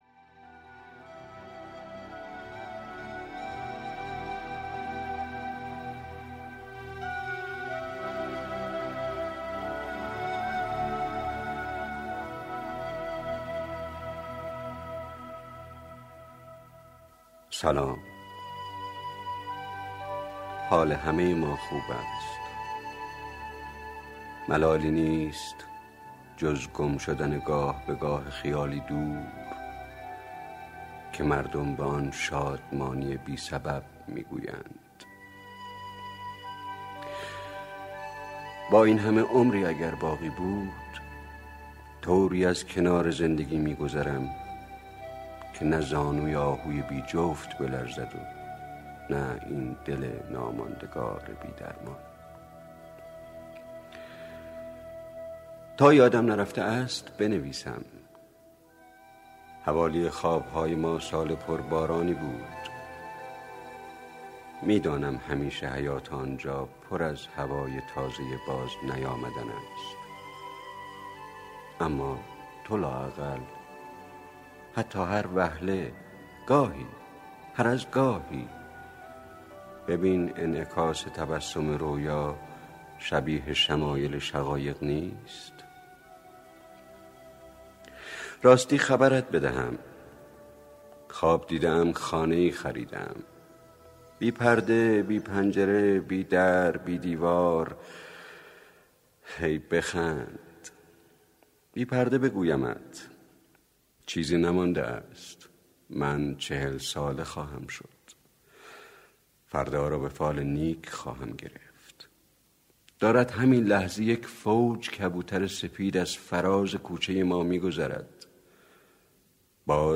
دکلمه خسرو شکیبایی – خسته ام
از شما دعوت می کنم دکلمه زیبای خسرو شکیبایی – خسته ام گوش دهید.